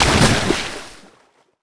water_splash_object_body_02.wav